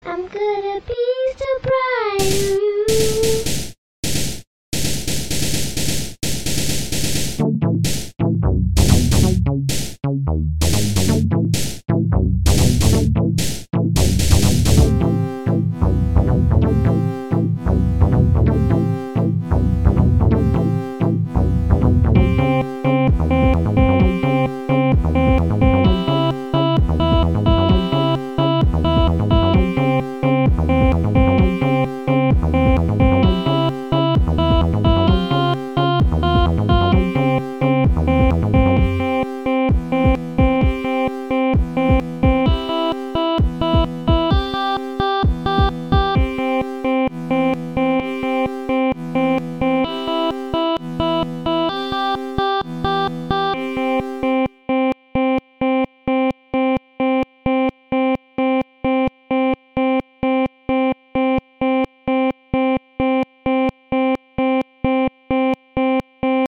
Contains another sample of the 1970s kid)